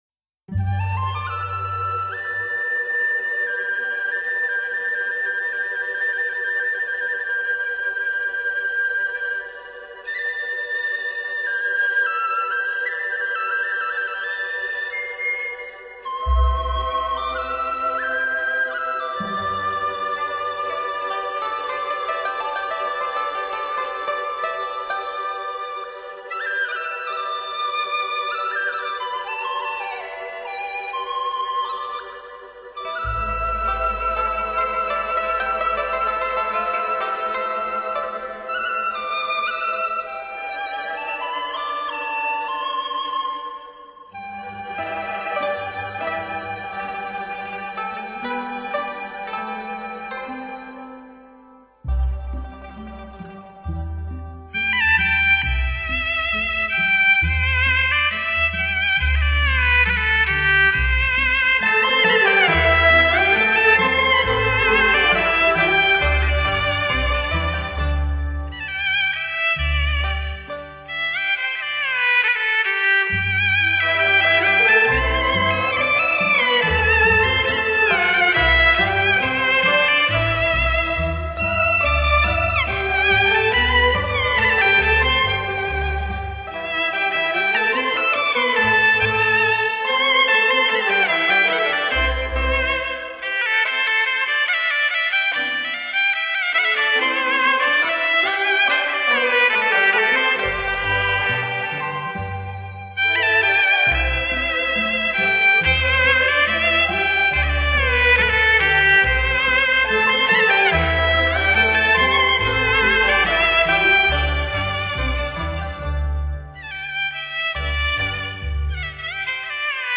主奏：齐奏
本专辑为广东音乐齐奏专辑，多为热烈欢乐的乐曲，又有文静清雅的乐曲，既有广东音乐著名的代表作，也有解放后新写的名曲
广东音乐采用正线、反线、乙反、士工等几种调，其音乐特点活泼轻快、细腻缠绵、艳郁华丽、流畅动听。